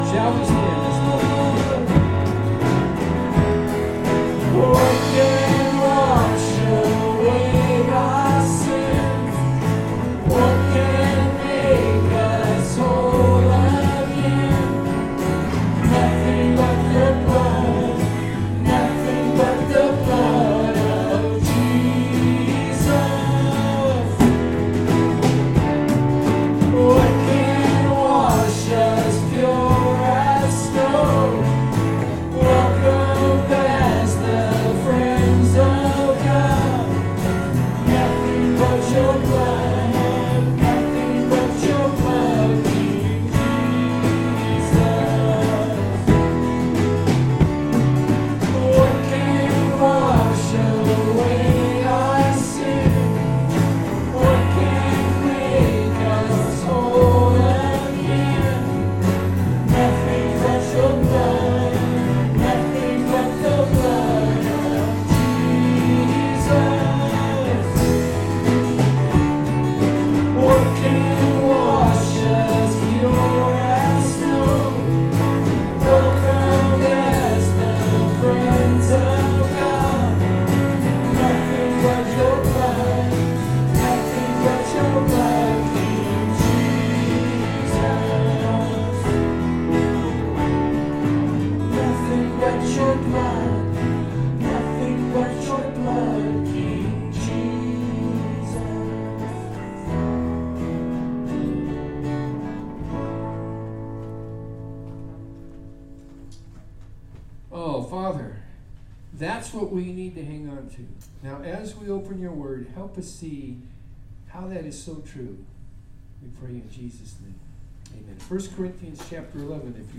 To Listen to an abbreviated version of the Worship Service, click here